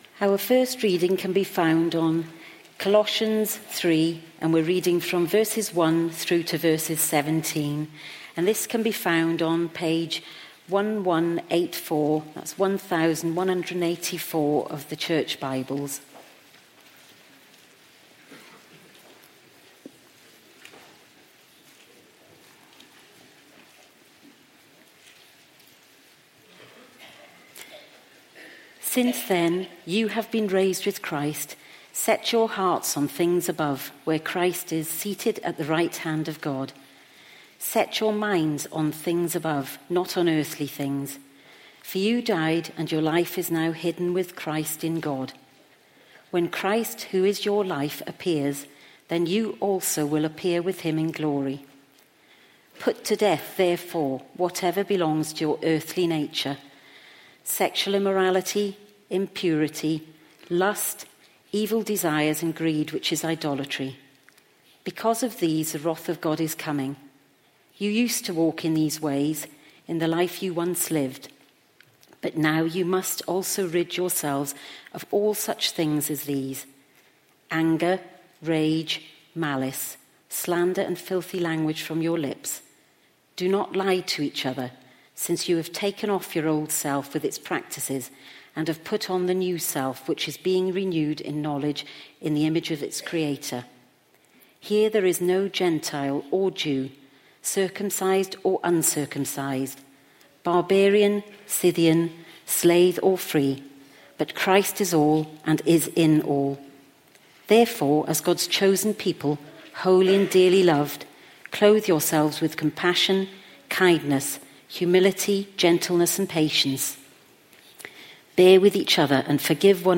Baptism & Confirmation Service - St Mary's Basingstoke